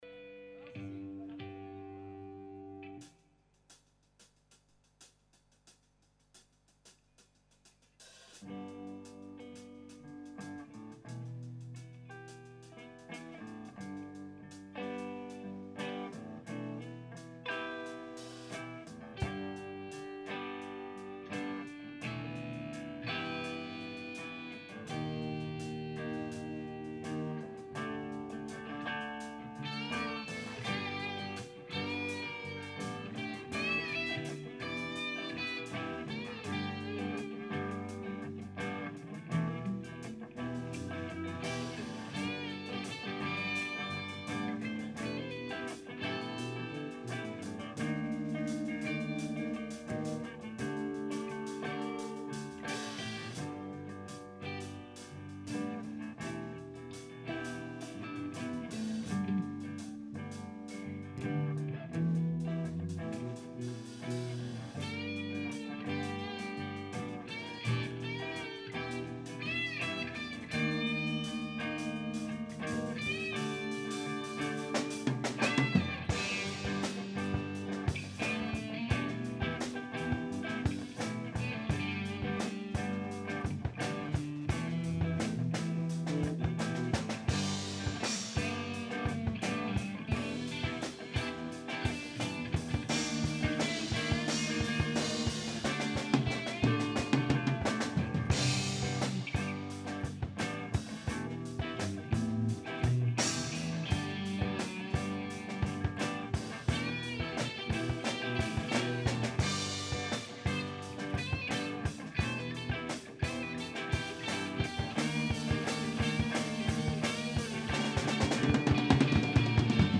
Prises de sons live ce qui implique parfois des petits défauts.
@ Répétition du 14 décembre 2008 @
Partie Note: enregistrement à bas niveau
#01 ~§¤ Lien pour télécharger la partie 01 (Impro)